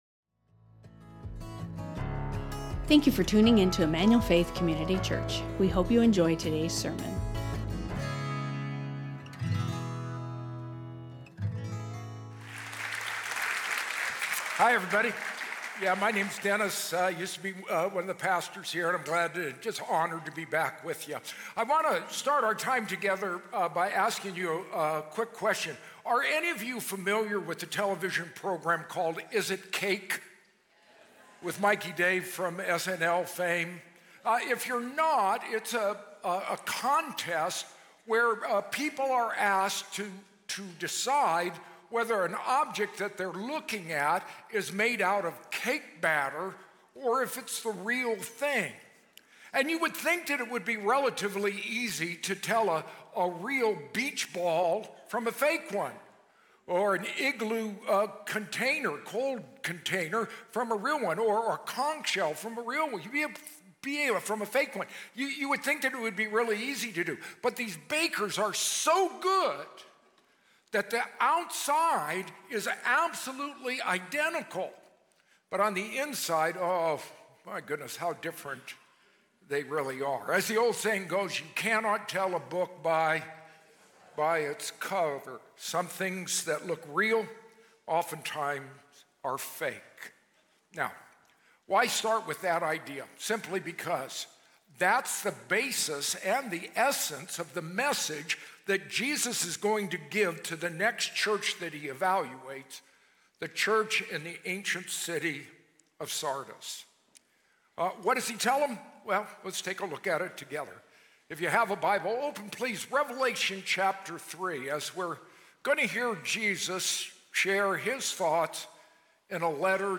An Empire Podcast Interview Special 41:20